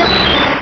Cri d'Amonistar dans Pokémon Rubis et Saphir.
Cri_0139_RS.ogg